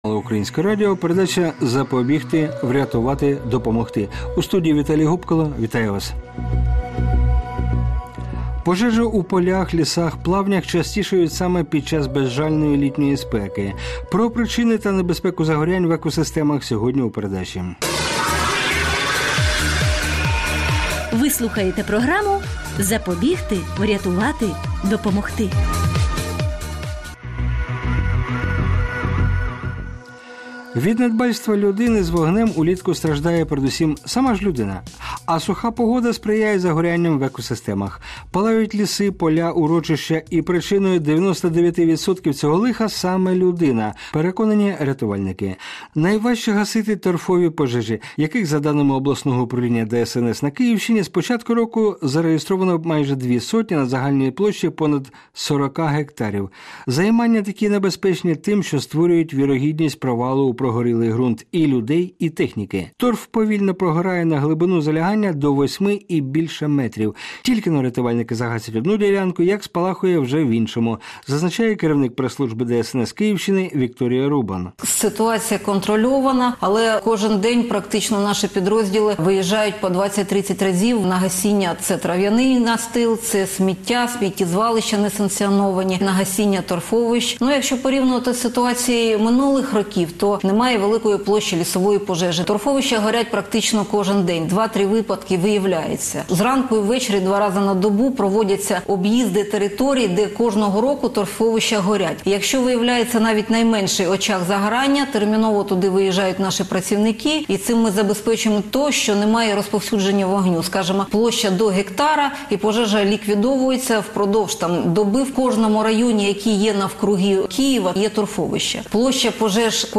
Виступ на Національній радіокомпанії України "Українське радіо" (Перший канал) у передачі "Запобігти. Врятувати. Допомогти"